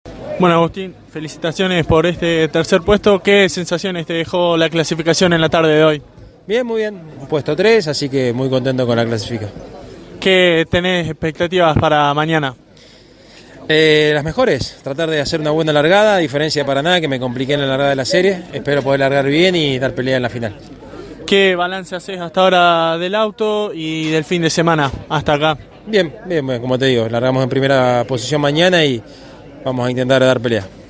Por último, los tres protagonistas más importantes de la clasificación dialogaron con CÓRDOBA COMPETICIÓN y estos son sus testimonios:
canapino-tercero.mp3